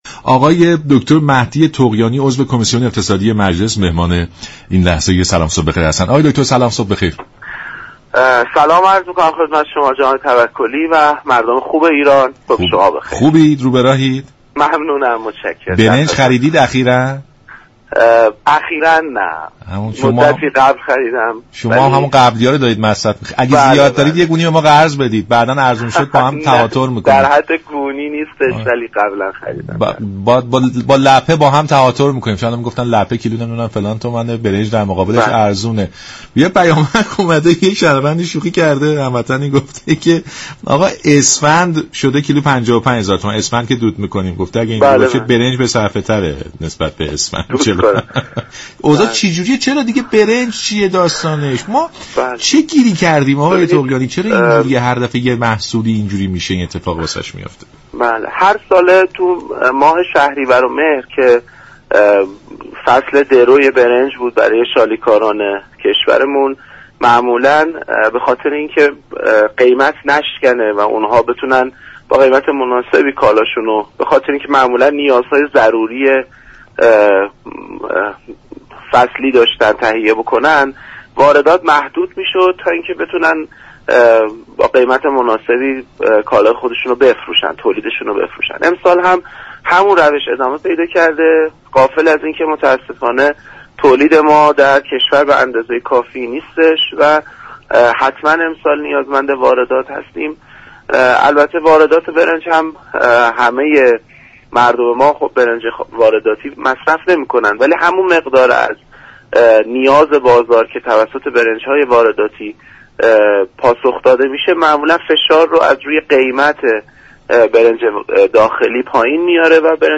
به گزارش شبكه رادیویی ایران، مهدی طغیانی عضو كمیسیون اقتصادی مجلس در برنامه «سلام صبح بخیر» درباره وضعیت بازار برنج در كشور اشاره كرد و گفت: ماه های شهریور و مهر، زمان درو برنج است، برای آنكه شالیكاران محصول خود را با قیمت مناسب به فروش برسانند در این دو ماه از واردات این محصول جلوگیری می شود.